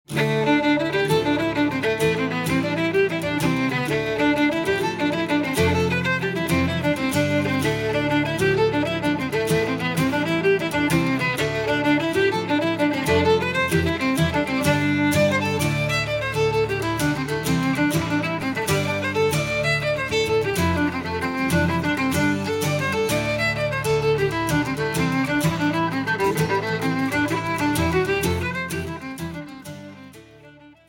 Na Poirt (jigs)